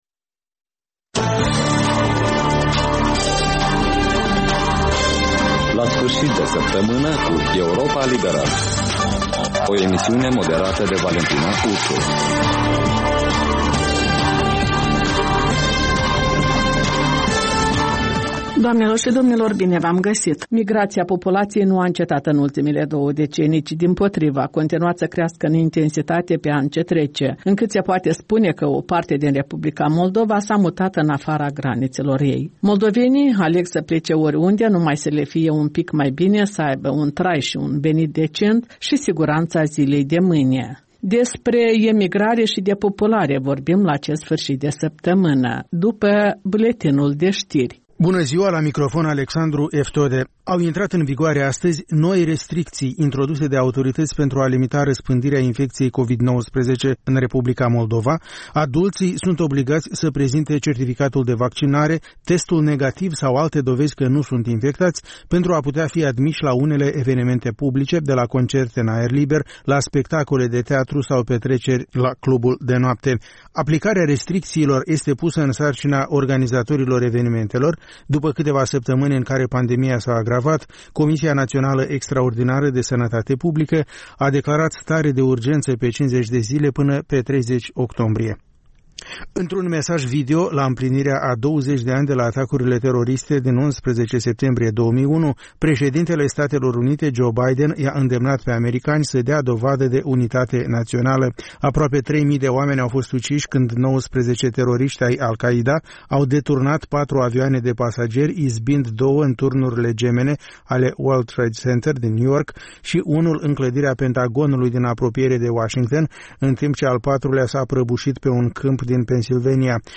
reportaje, interviuri, voci din ţară despre una din temele de actualitate ale săptămînii. In fiecare sîmbătă, un invitat al Europei Libere semneaza „Jurnalul săptămînal”.